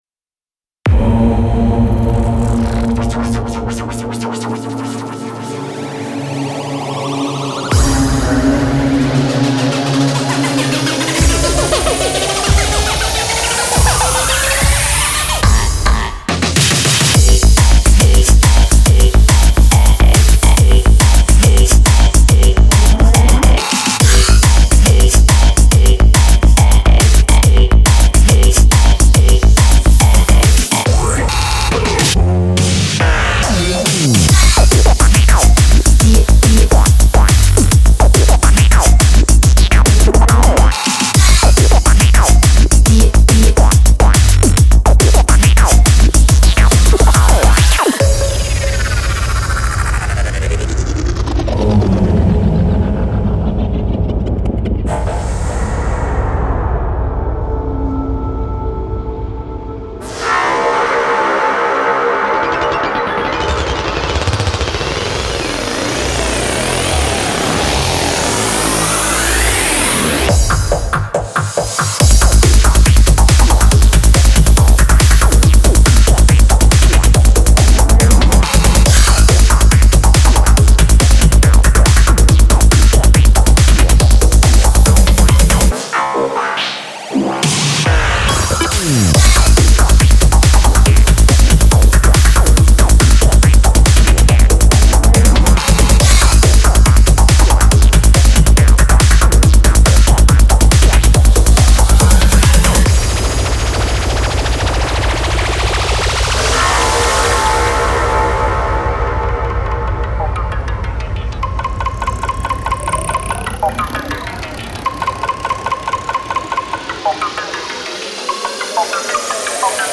包含各种夏日迷幻般的前卫迷幻声音！
24位质量